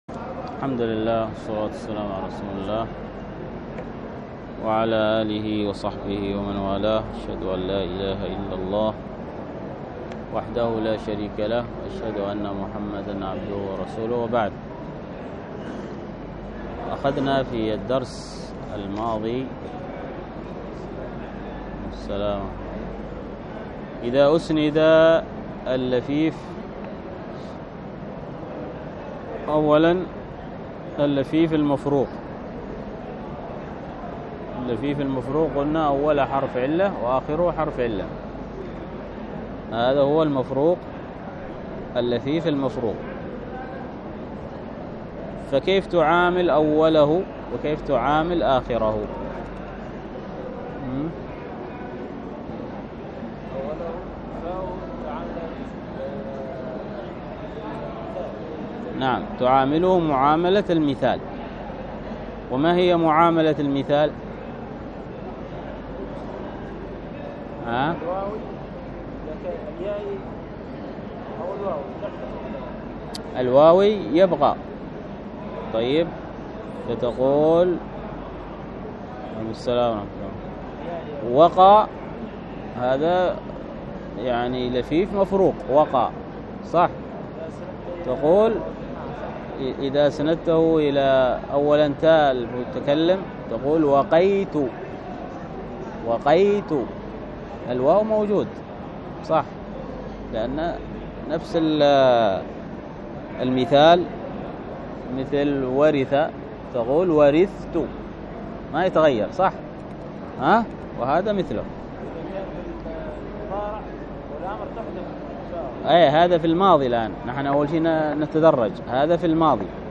الدرس في التعليق على مقدمة سنن الدارمي 55، ألقاها